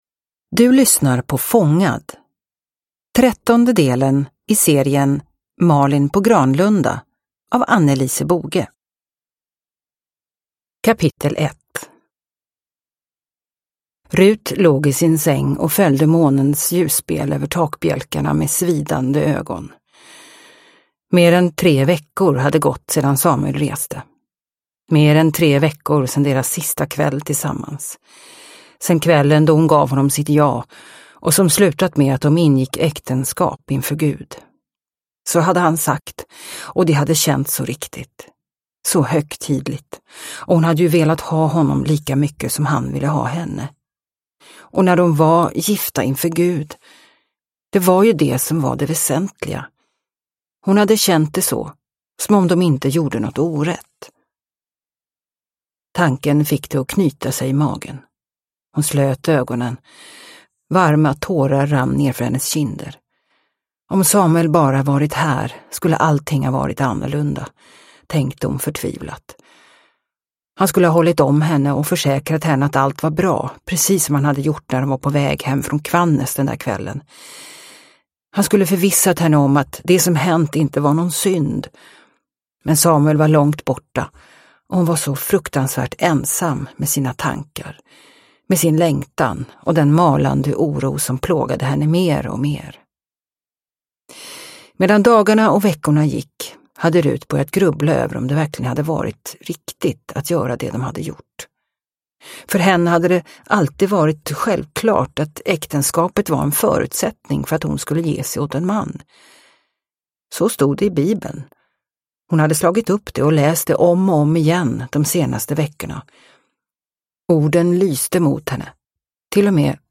Fångad – Ljudbok – Laddas ner